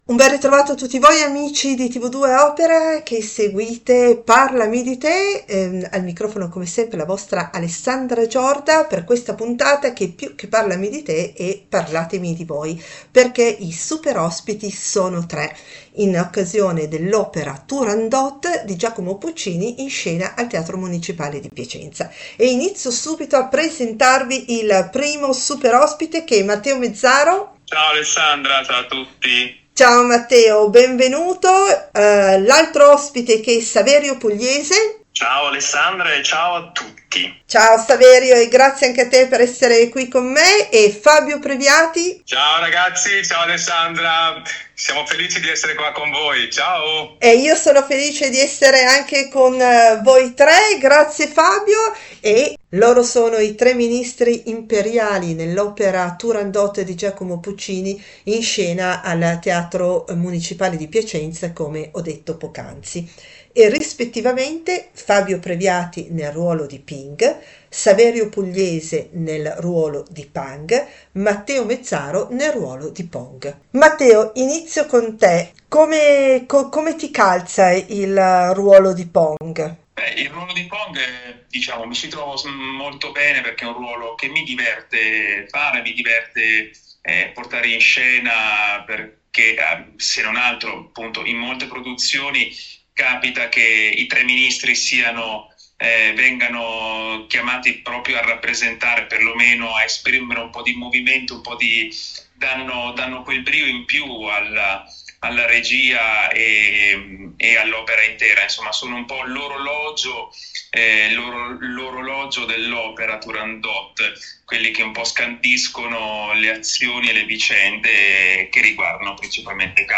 Un’intervista spassosa con tre artisti tanto bravi quanto simpatici